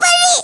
File File history File usage JPurinDamageFly02.wav  (WAV audio file, length 0.4 s, 353 kbps overall) Unused Jigglypuff audio This file is an audio rip from a(n) Wii game.